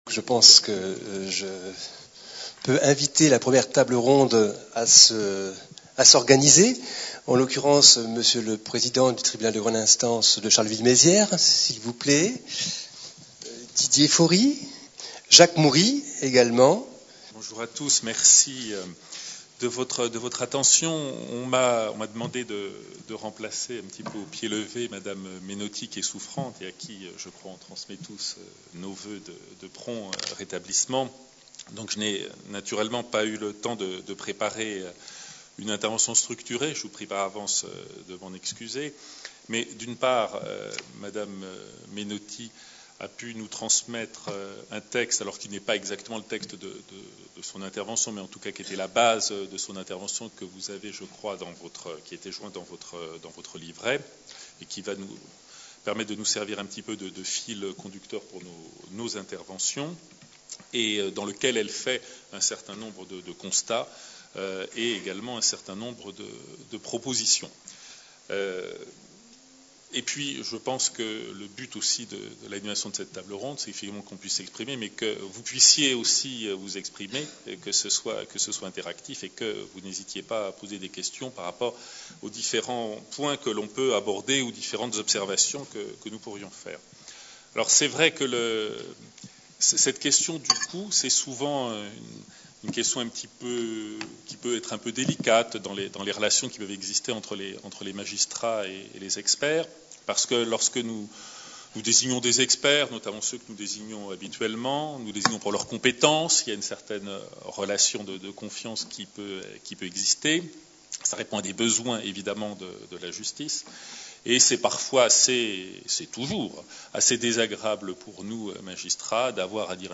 Conférence enregistrée lors du 7ème Colloque de la Compagnie des Experts de Reims.